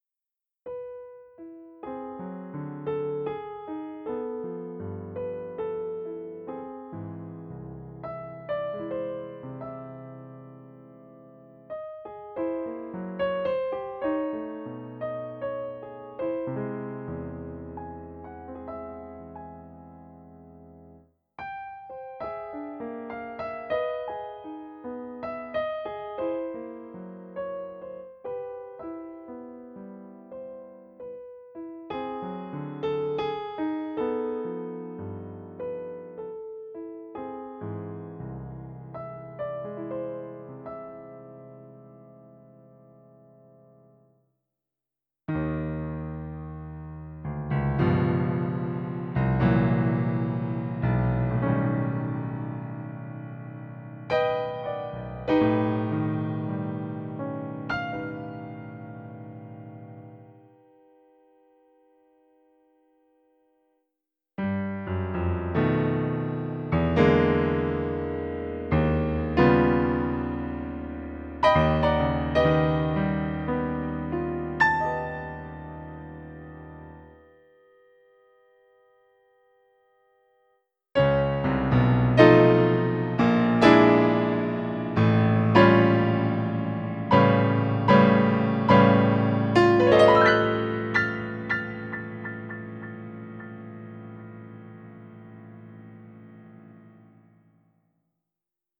Кстати, это не Скрябин, это музыка Лядова и Черепнина.